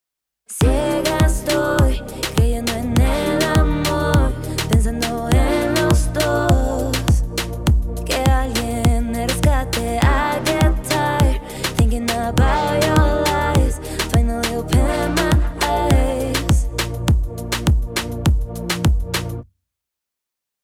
バックトラックを含めた状態で、ヴォコーダーなしの状態と、バックコーラスとしてのヴォコーダーを加えたバージョンを聞いてみます。
完成形（ヴォコーダーあり）
mix_vocoder_on.mp3